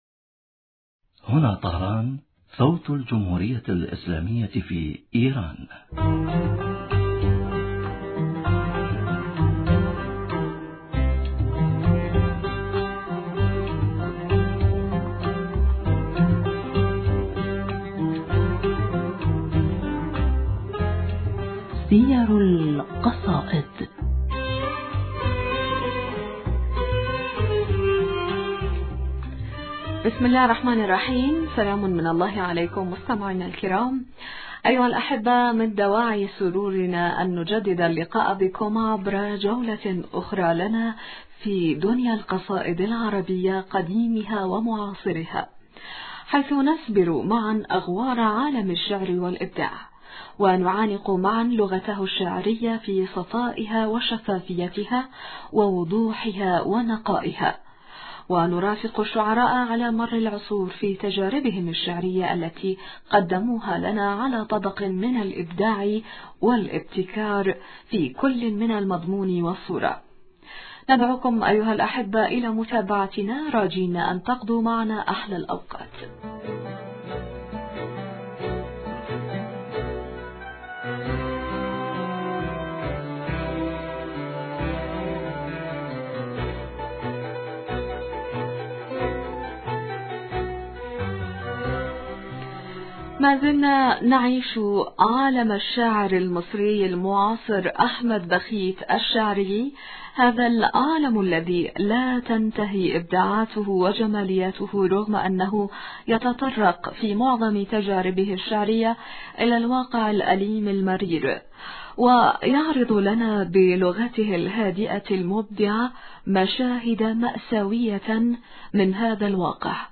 المحاورة:
المحاورة : ما زلنا نعيش عالم الشاعر المصري المعاصر أحمد بخيت هذا العالم الذي لاتنتهي ابداعاته وجمالياته رغم أنه يتطرق في معظم تجاربه الشعرية الى الواقع الأليم المرير ويعرض لنا بلغته الهادئة المبدعة مشاهد مأساوية من هذا الواقع ولكن بريشة الفنان الموهوب الذي يعرف كيف يختار الألوان وكيف يمزج بينها ليقدم لنا لوحات من نوع آخر، لوحات تعرض لنا المشاهد المأساوية بألوان مشرقة زاهية تطلق الأمل والتفاؤل من بين مساحات الحزن التي تغلفها هنا وهناك فتعالوا معنا أيها الأحبة لنستكمل جولتنا بين أبيات قصيدة الشاعر "حرير العاشقات" قلد رأينا أن هذه اللوحة الكاملة تبقى ناقصة شوهاء إن لم نستكمل قراءتها في جميع المشاهد التي تعرضها علينا. تابعونا عند المزيد من الأبيات بعد الفاصل .